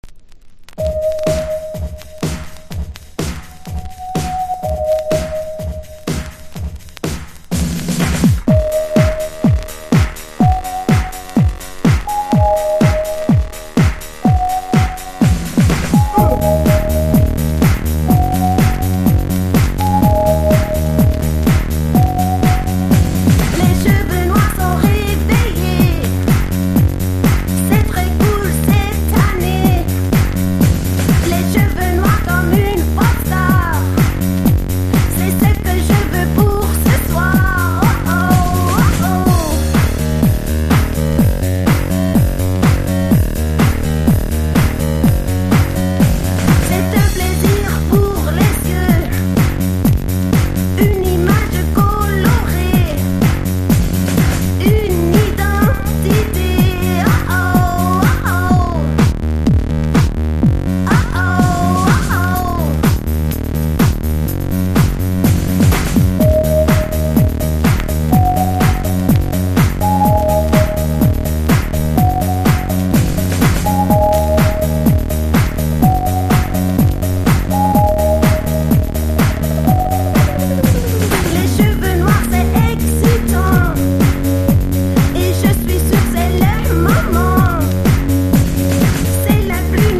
# ELECTRO